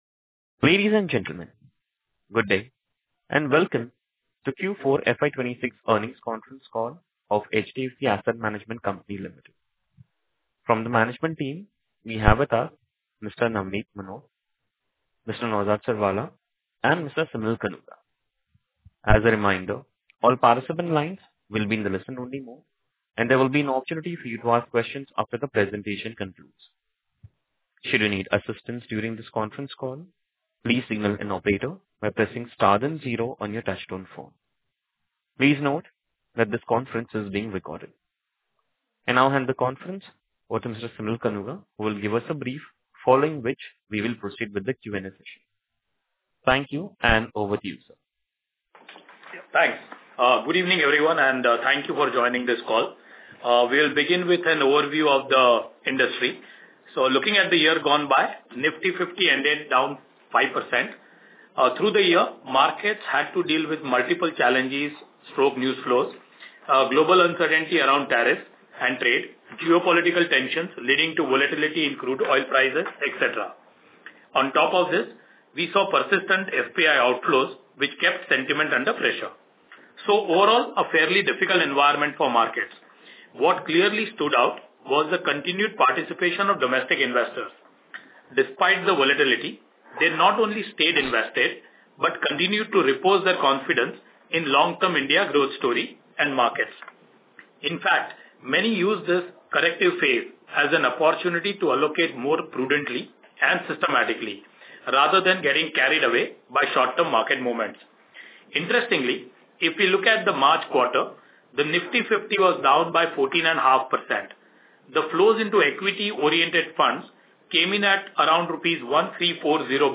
Concalls
Q4 FY26 Audio Recording of the Earnings Call.mp3